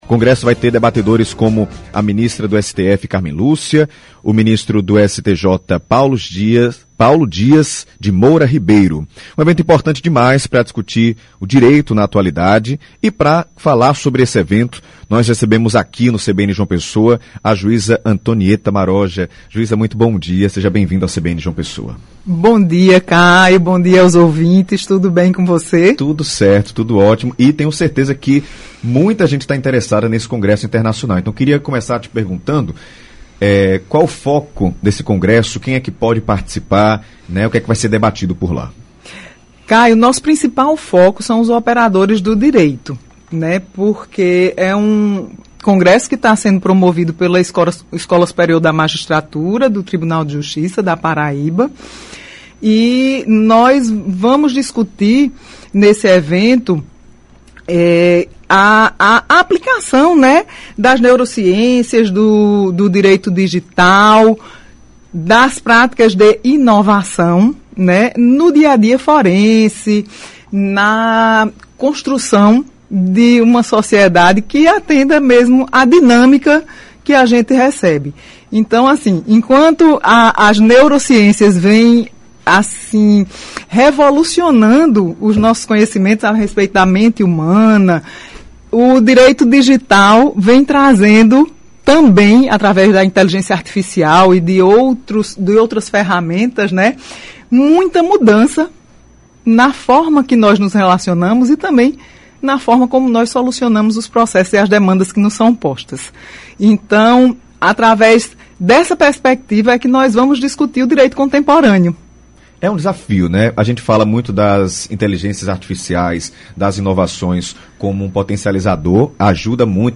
E para falar sobre esse evento o CBN João Pessoa Recebe a Juíza Antonieta Maroja.